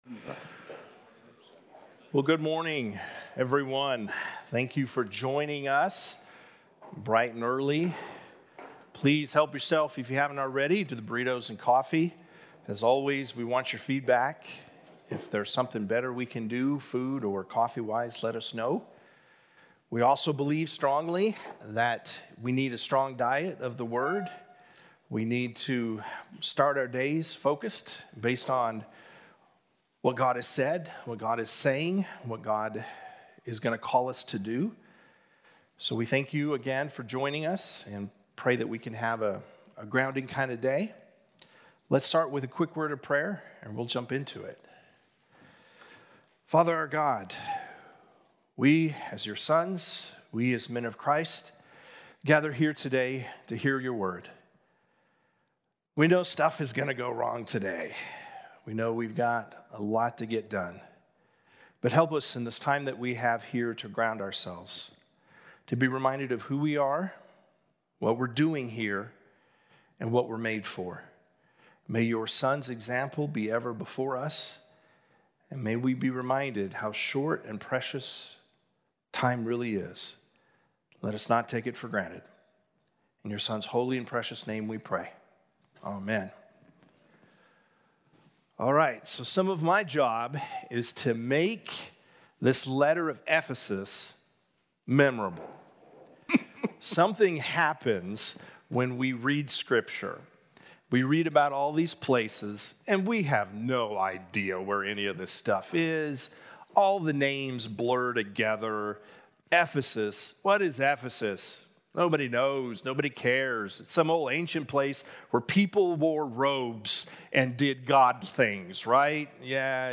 Men’s Breakfast Bible Study 7/28/20
Mens-Bible-Study-7_28.mp3